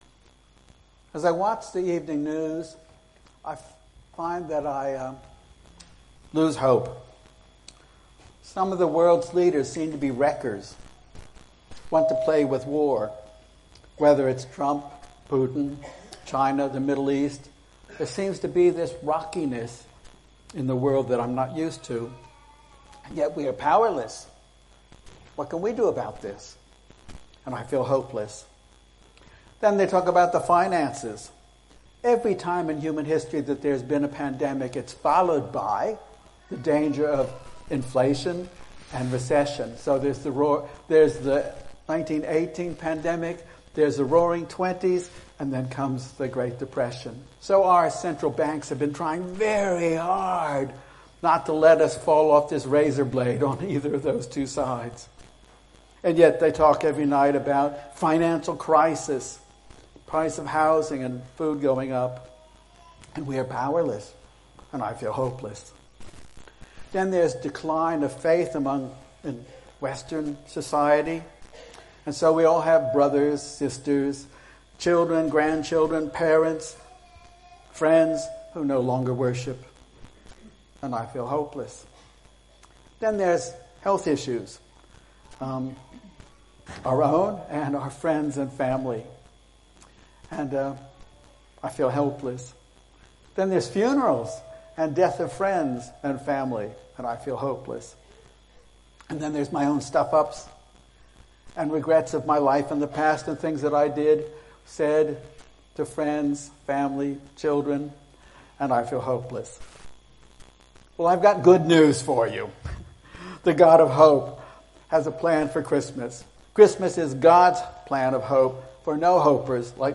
SBLC Sermon
Sermons Online Audio Sunday 8 Dec SBLC Sermon